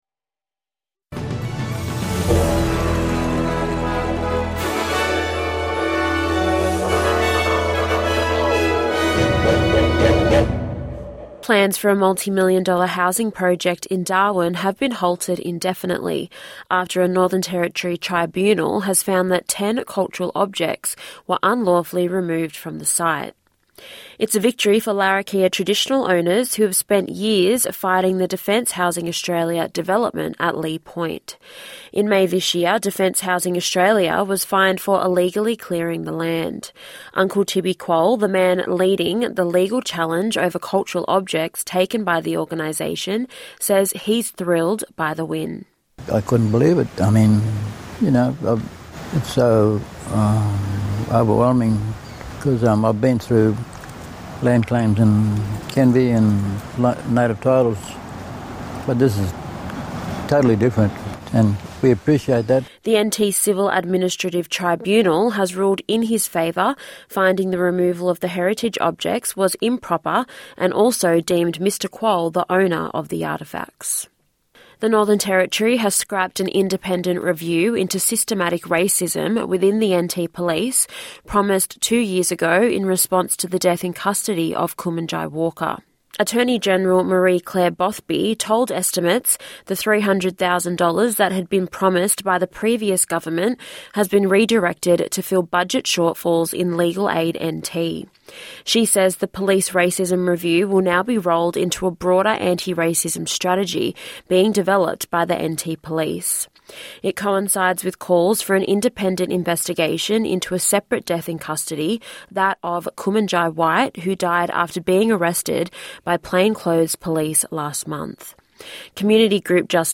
NITV Radio News - 18/6/2025